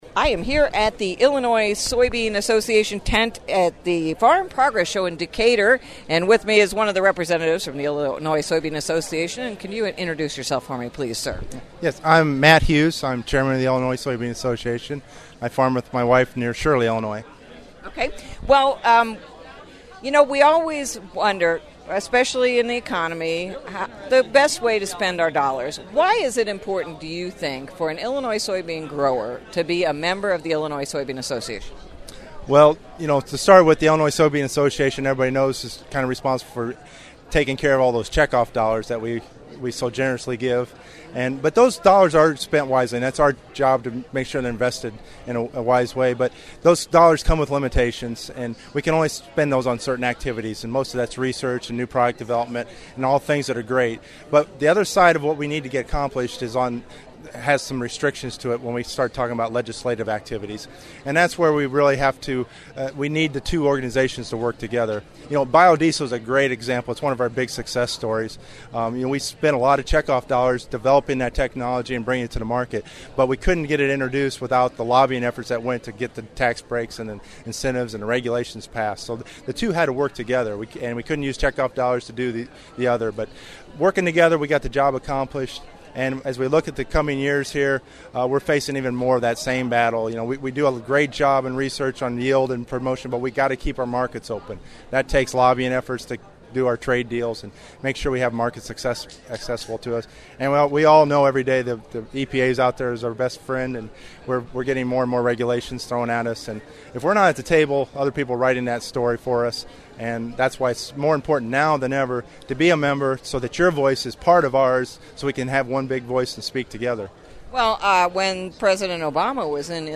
interview
2011 Farm Progress Show Photo Album